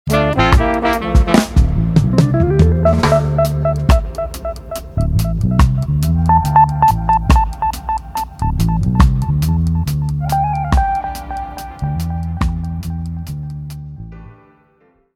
• Качество: 320, Stereo
без слов
инструментальные
звонкие
джаз
Джазовая нарезка